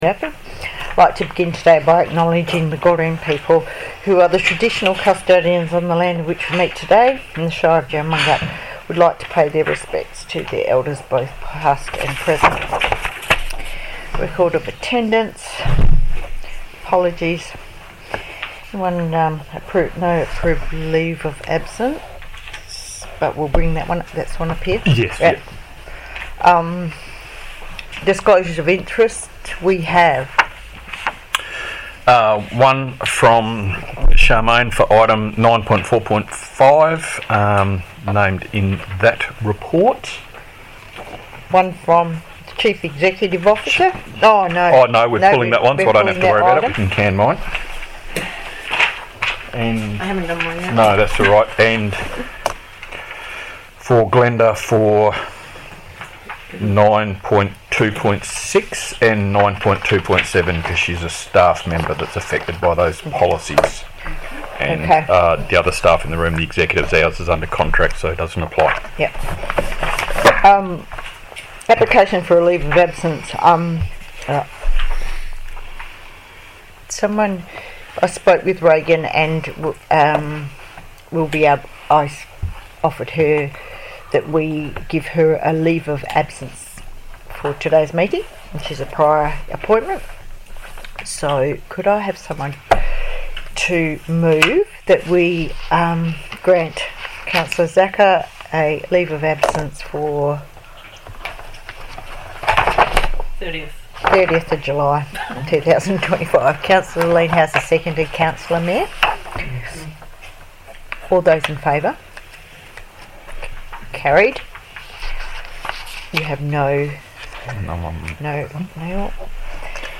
Ordinary Council Meeting - 30 July 2025 - Recording (30.21 MB)